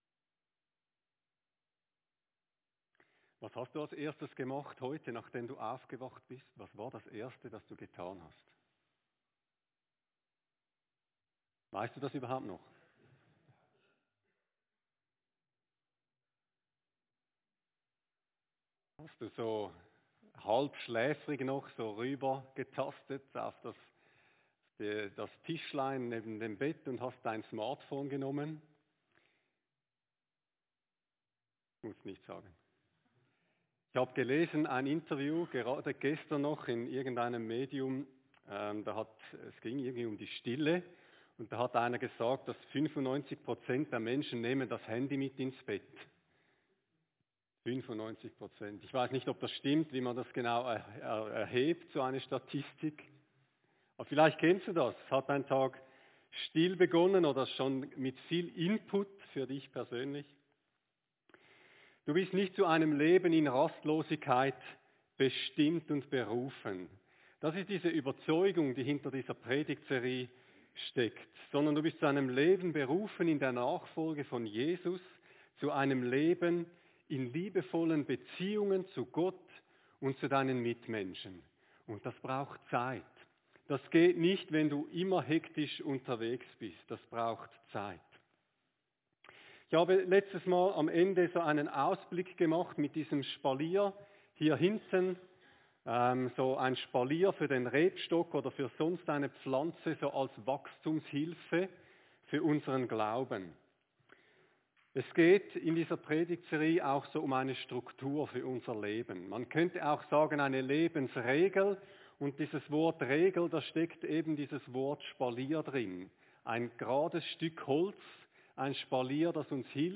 Predigt-19.1.25.mp3